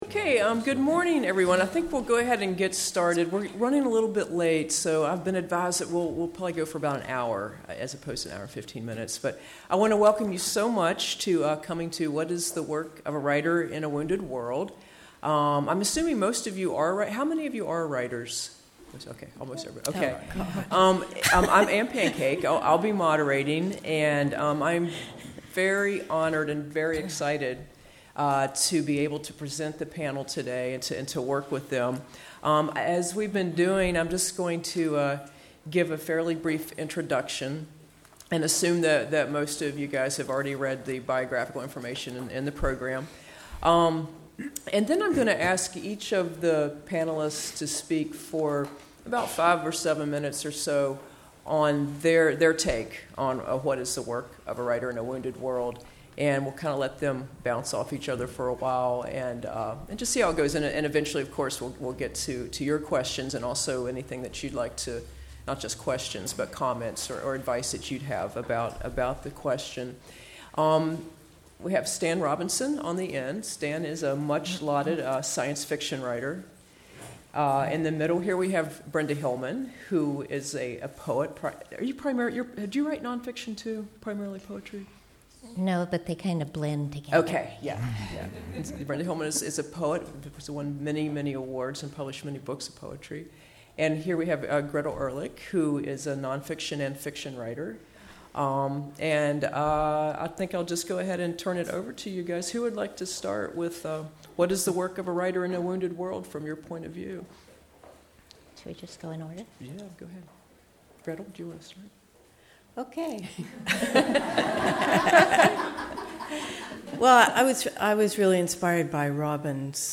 GOH2015Panel1_Work.mp3